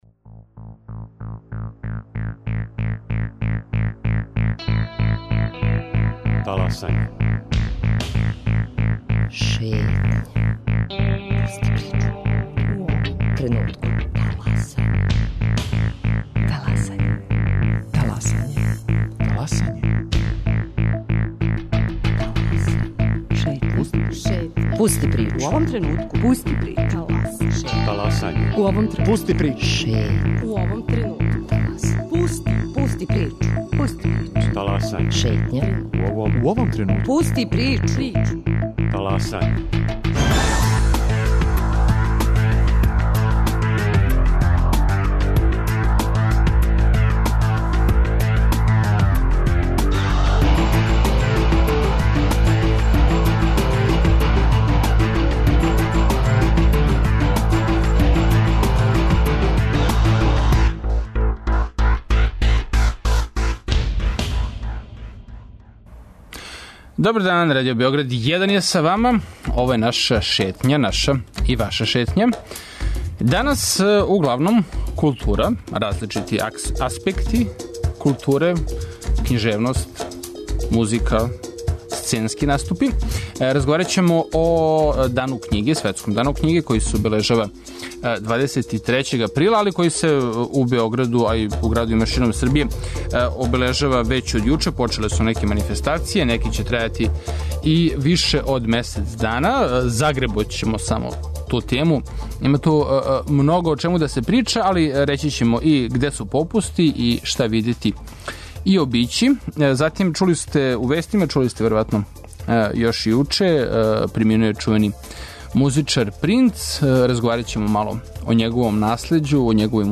У Београду је почео 7. Интернационални фестивал аргентинског танга. Присуствовали смо радионици за почетнике у Студентском културном центру, забележили атмосферу и покушали да сазнамо како начинити прве танго кораке.